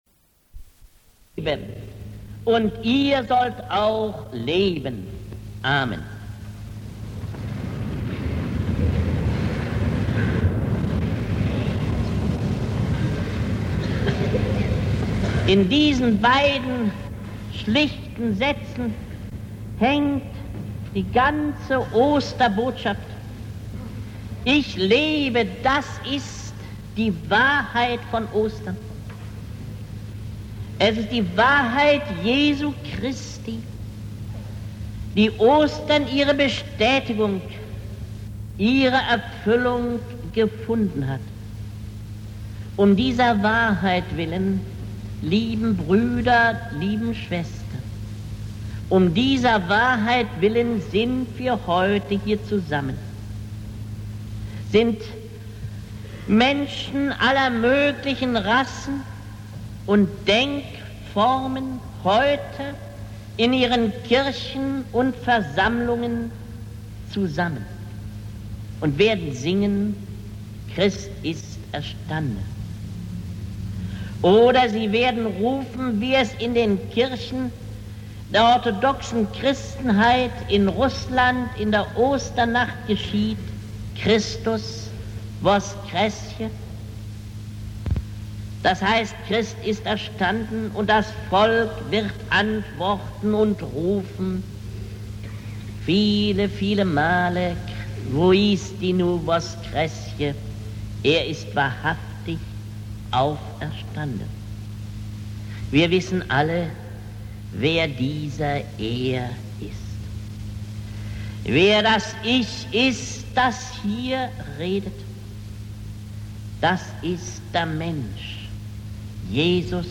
Klicken Sie hier, um die Originalaufnahme einer Predigt von Hans Joachim Iwand zu hören.
Iwand-Osterpredigt-Joh-14-.mp3